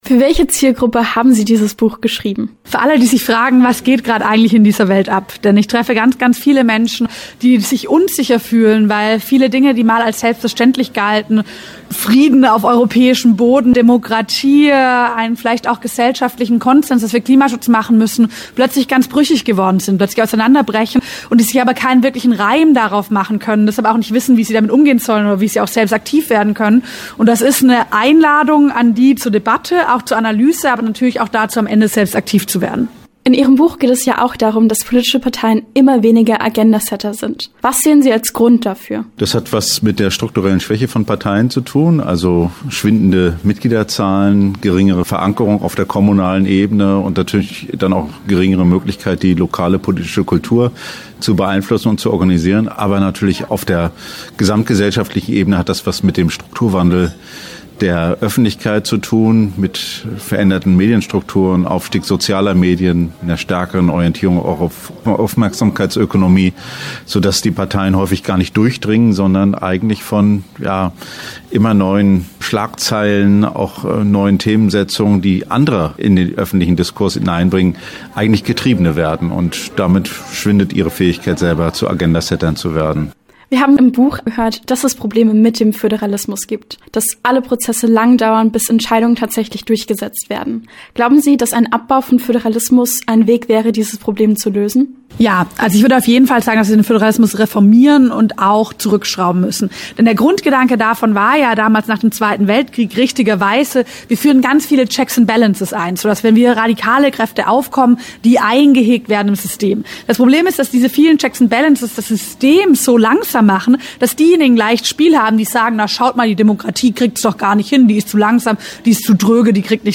„Der große Umbruch“: Die Sachbuch-Autoren Ricarda Lang und Steffen Mau im Gespräch - Okerwelle 104.6